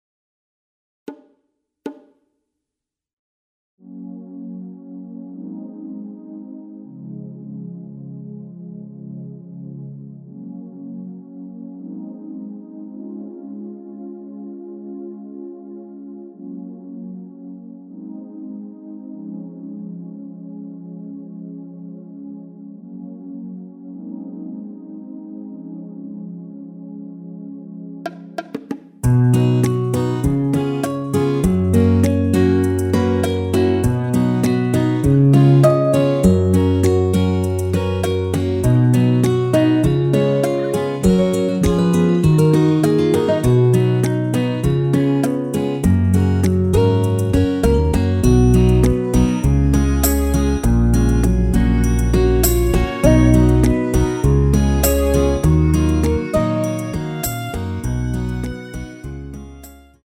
시작부분이 반주가 없이 진행 되는곡이라 스트링으로 편곡 하여놓았습니다.(미리듣기 참조)
F#
앞부분30초, 뒷부분30초씩 편집해서 올려 드리고 있습니다.